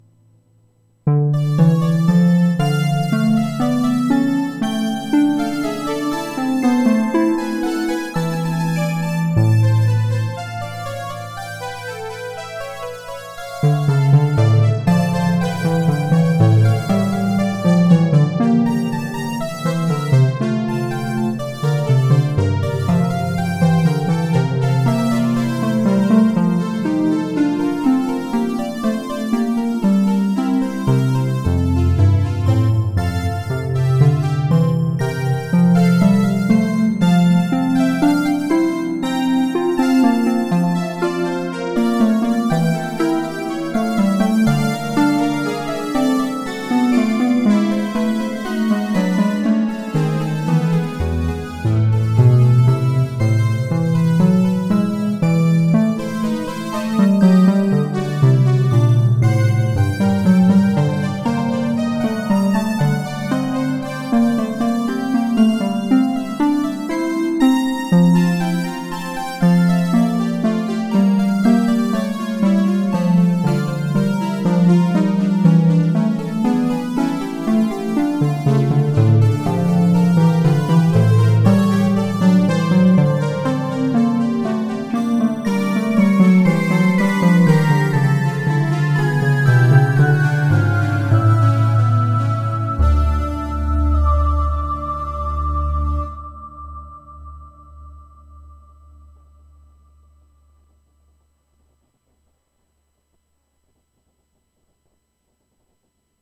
Bach Sinfonia No. 4 in D Minor BWV 790 (synthesized)
One of Bach's three-part inventions, arranged for and played on a Waldorf Blofeld synthesizer.